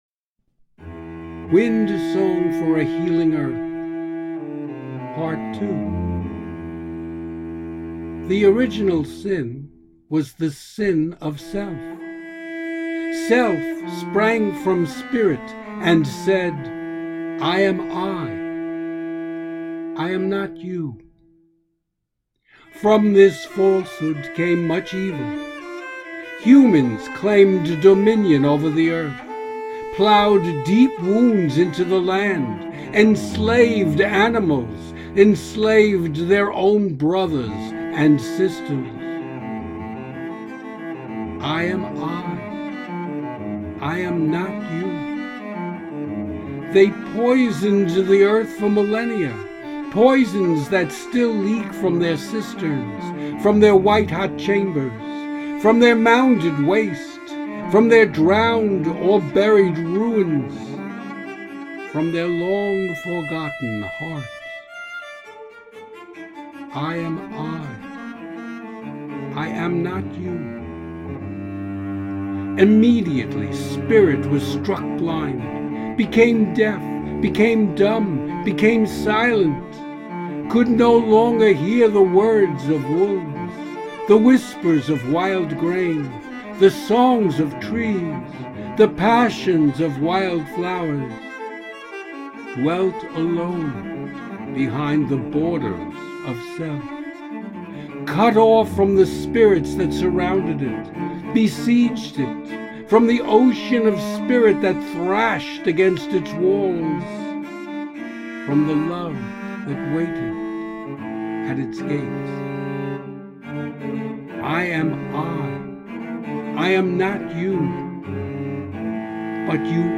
Solo Cello Passion.